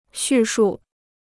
叙述 (xù shù): erzählen; schildern.